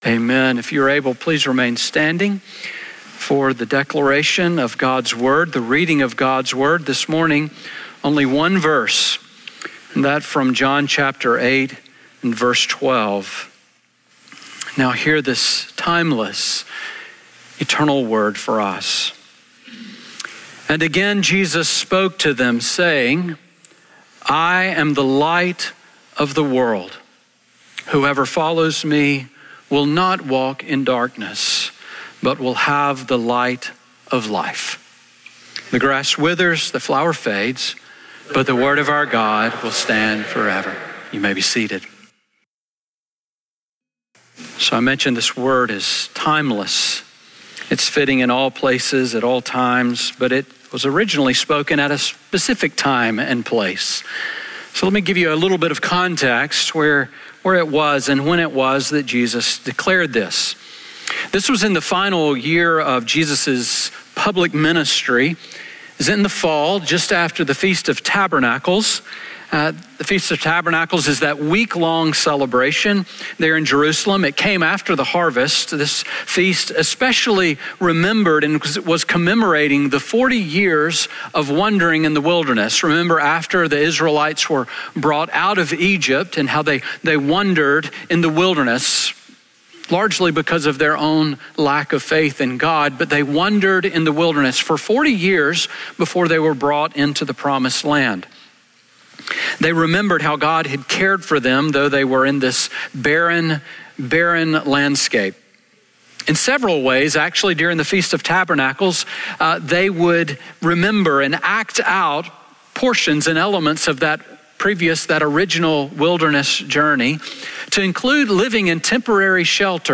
Sermon on John 8:12 from December 21